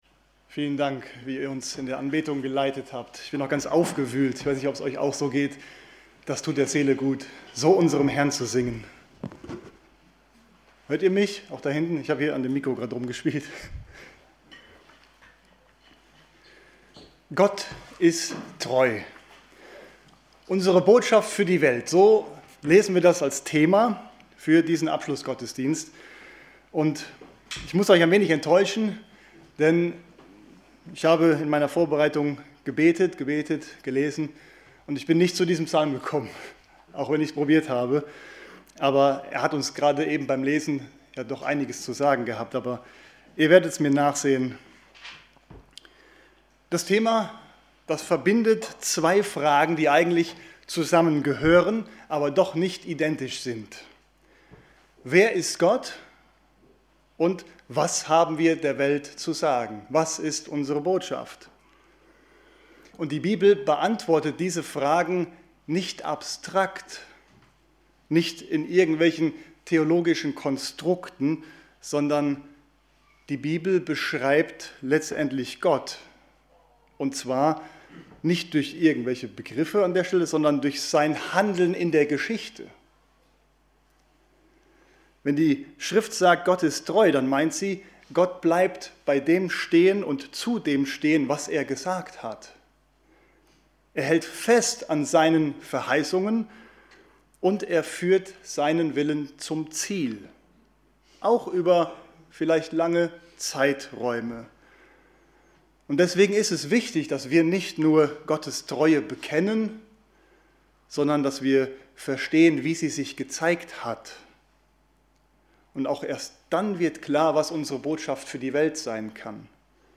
Predigten – Evangelische Gemeinschaft Kredenbach